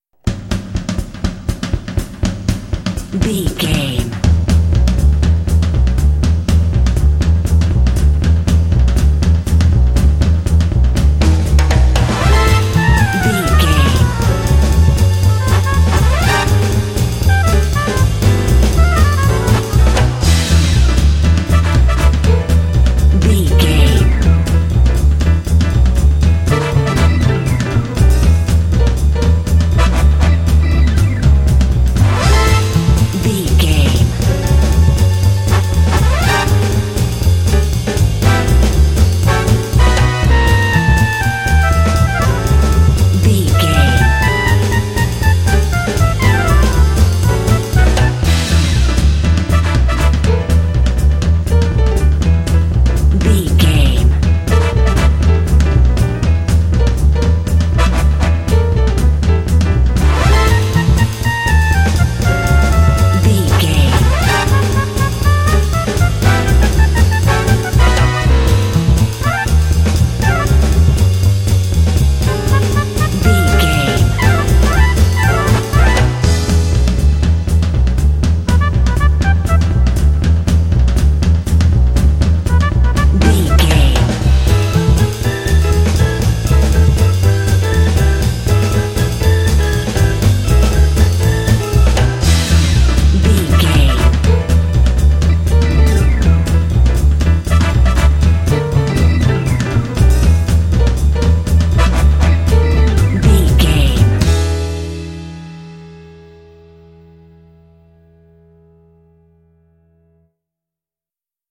Uplifting
Aeolian/Minor
Fast
driving
energetic
lively
cheerful/happy
drums
double bass
piano
electric organ
brass
big band
jazz